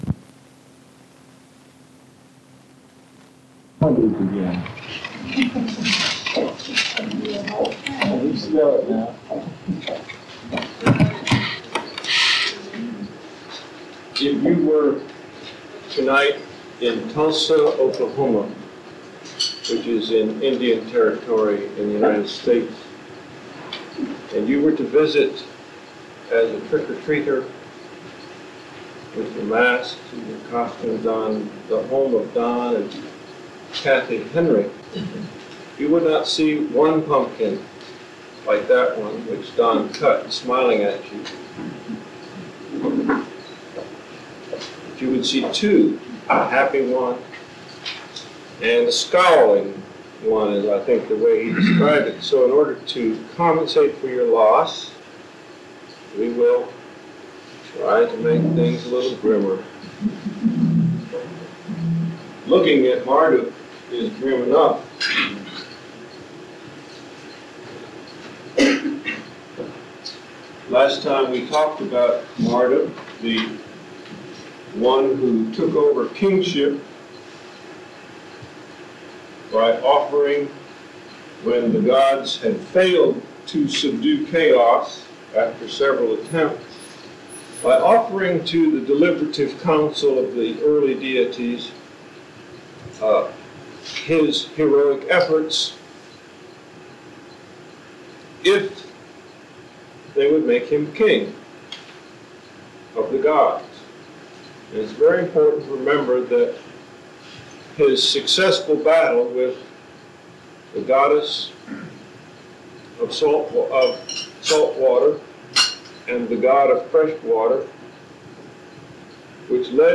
An Archaeological Interpretation of Babylon in the 6th Century B. C.; Humans, Deities, and their relationships, lecture #5: The Moon and Sun Guide: Nobu and Shemash